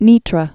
(nētrə)